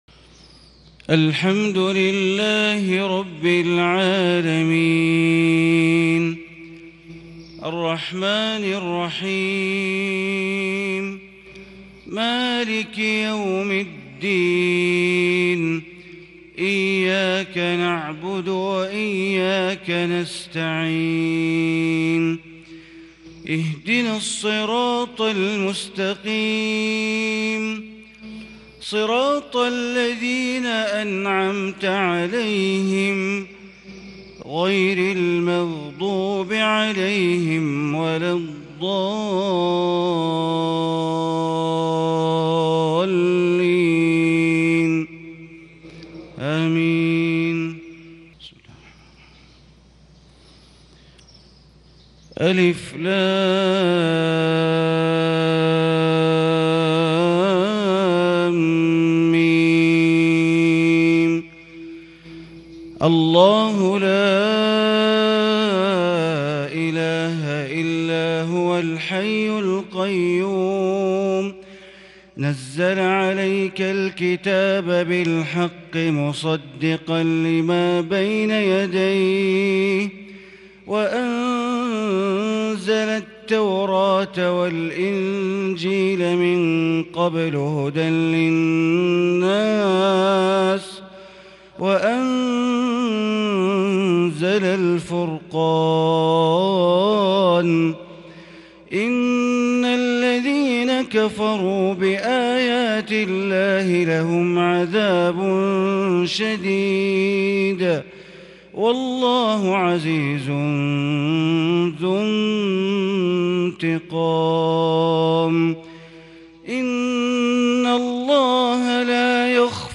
فجر الأحد 8-8-1442هـ من سورة آل عمران {1-18} > 1442 هـ > الفروض - تلاوات بندر بليلة